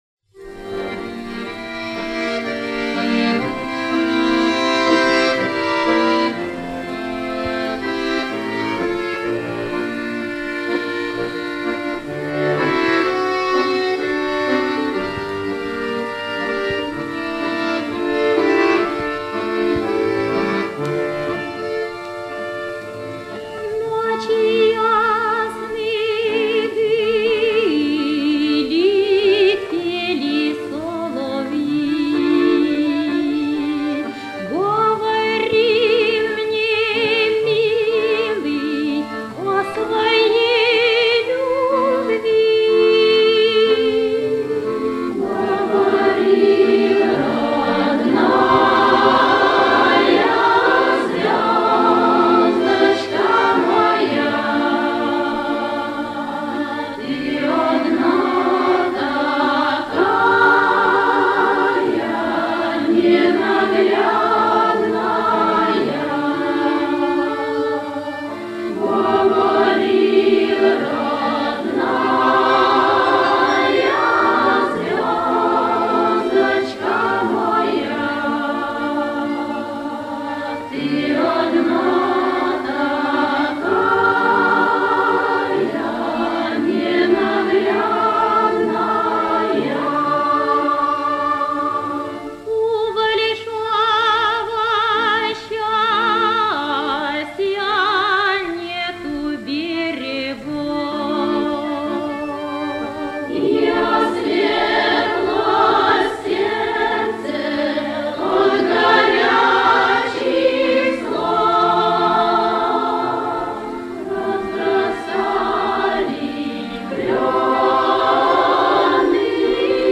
Матрица 34176 (патефонная)